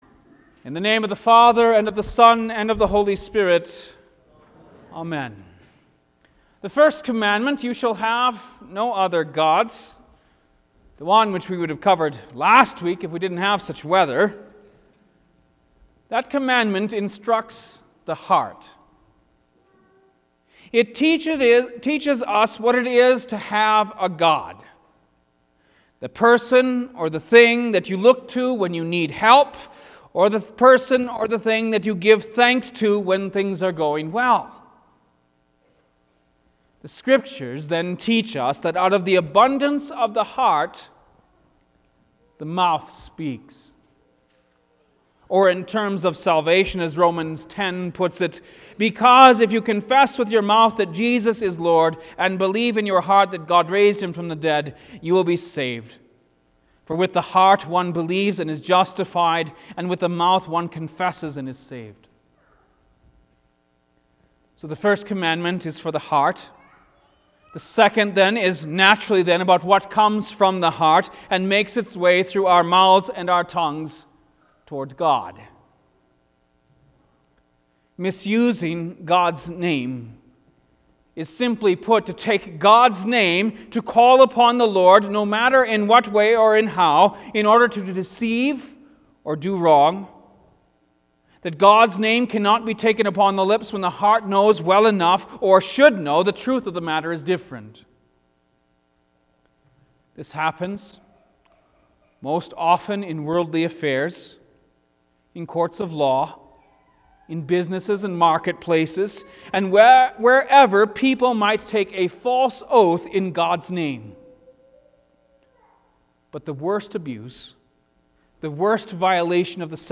Lenten Midweek Service Two